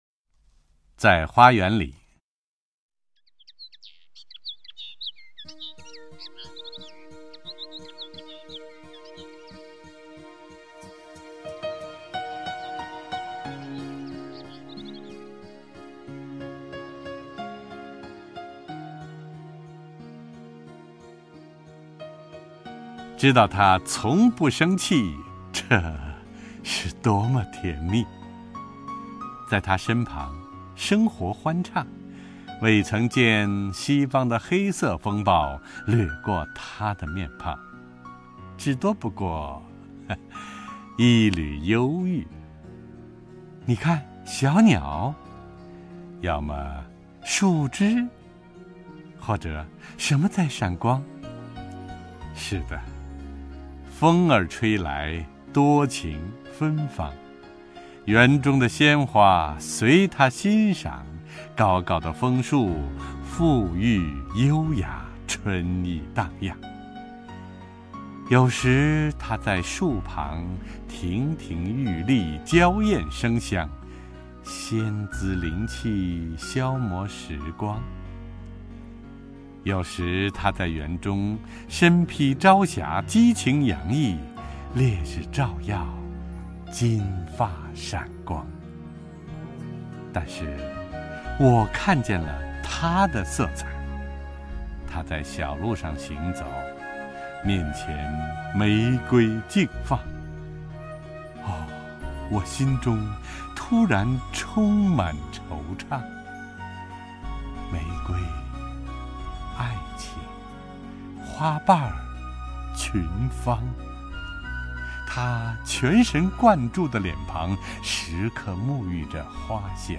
首页 视听 名家朗诵欣赏 陈铎
陈铎朗诵：《在花园里》(（西班牙）阿莱桑德雷)